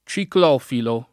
[ © ikl 0 filo ]